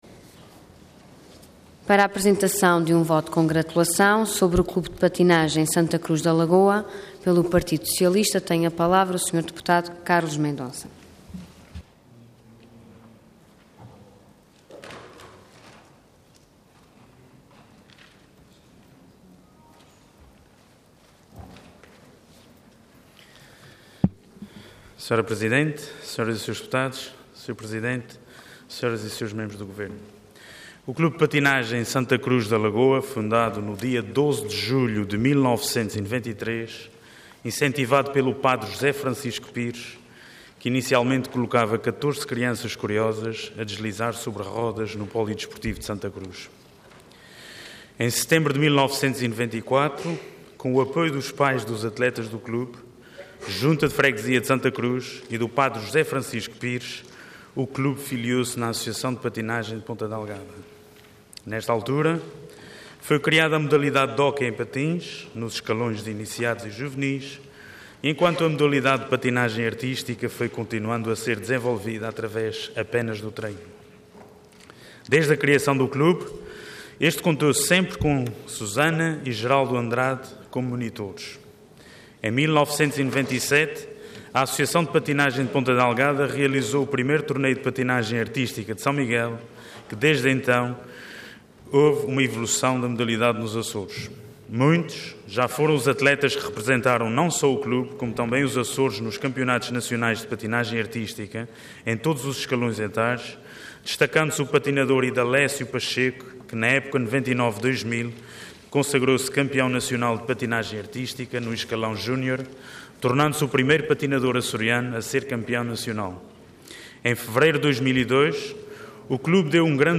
Detalhe de vídeo 11 de julho de 2013 Download áudio Download vídeo Diário da Sessão Processo X Legislatura Pelos feitos alcançados pelo Clube de Patinagem Santa Cruz da Lagoa. Intervenção Voto de Congratulação Orador Carlos Mendonça Cargo Deputado Entidade PS